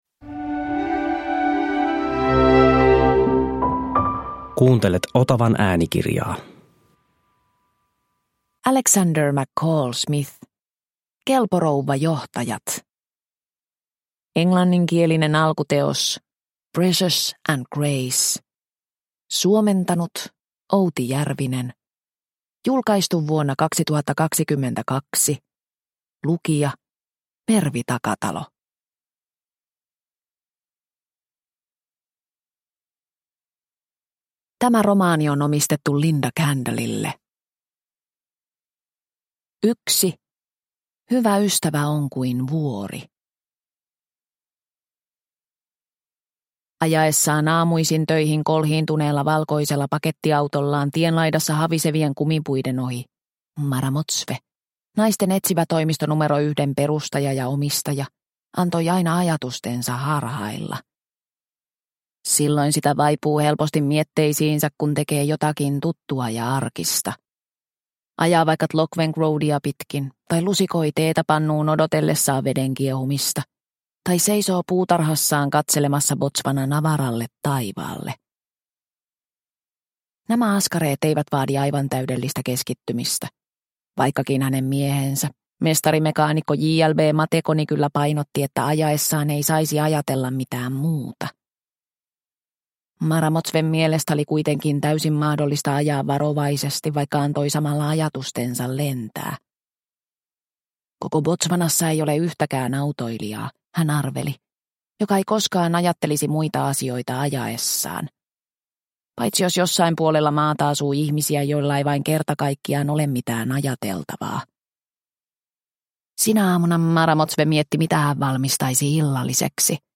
Kelpo rouva johtajat – Ljudbok – Laddas ner